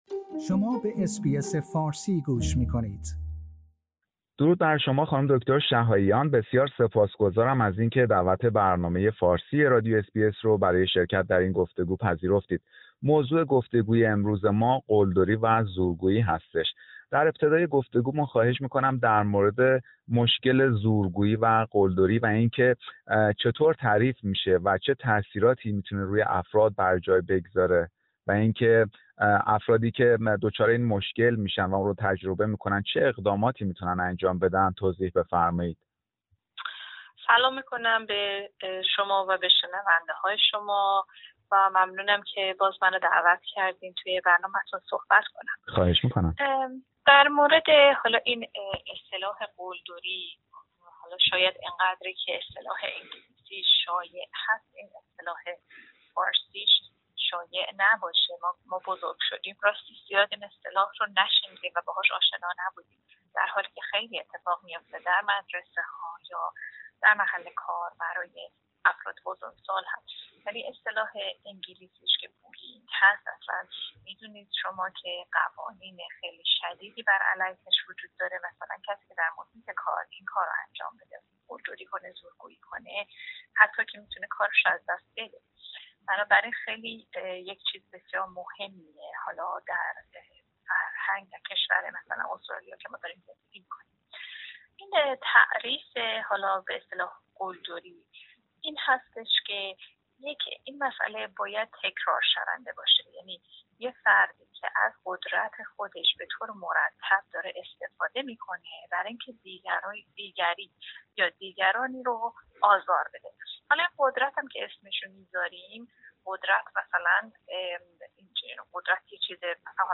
برنامه فارسی رادیو اس بی اس در همین خصوص گفتگویی داشته